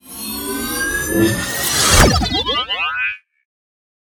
beam.ogg